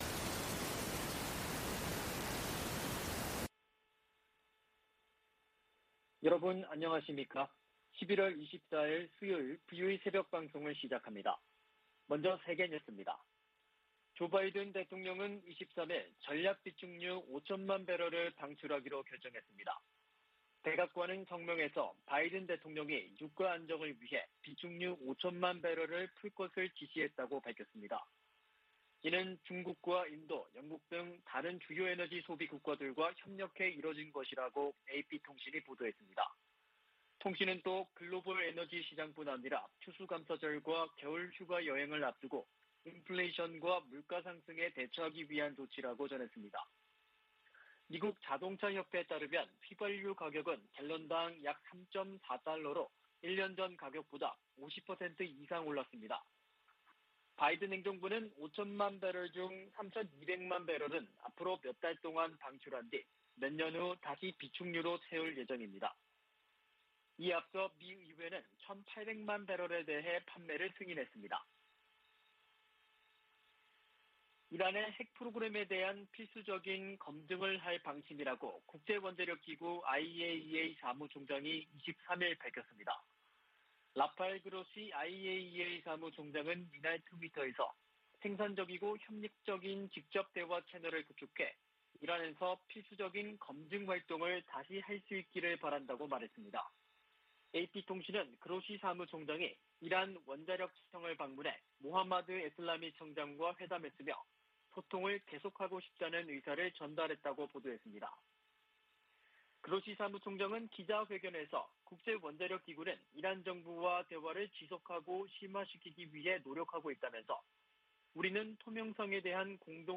VOA 한국어 '출발 뉴스 쇼', 2021년 11월 24일 방송입니다. 세계 각국이 북한 해외 노동자 송환 보고서를 유엔 안보리에 보고하도록 돼 있지만 보고 비율은 20% 미만에 그치고 있습니다. 미국이 핵 문제와 관련해 이란과 ‘간접 협상’을 재개하지만 북한과의 협상은 여전히 재개 조짐이 없습니다. 미 국무부는 북한 등 문제 해결에 미,한, 일 3각 공조의 중요성을 거듭 강조하며, 지난주 열린 3국 차관협의회는 매우 건설적이었다고 밝혔습니다.